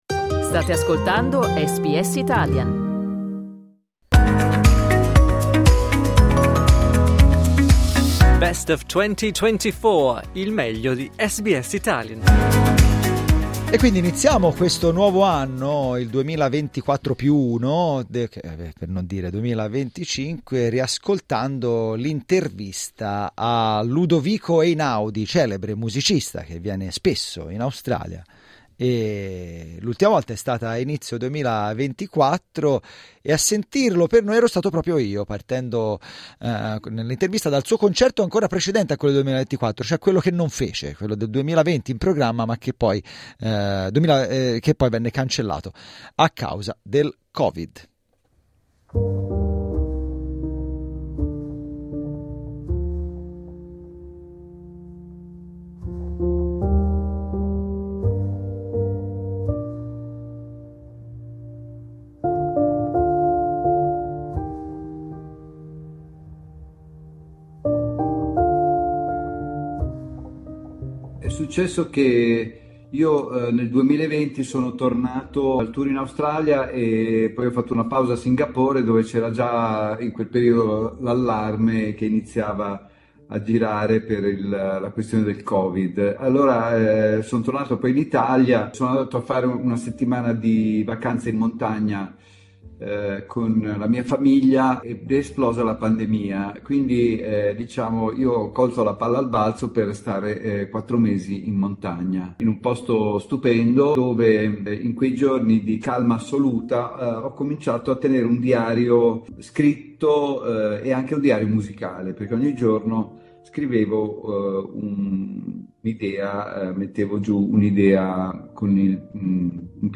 Dai nostri archivi, l'intervista al celebre musicista italiano, che un anno fa è tornato in Australia per esibirsi e promuovere il suo ultimo album.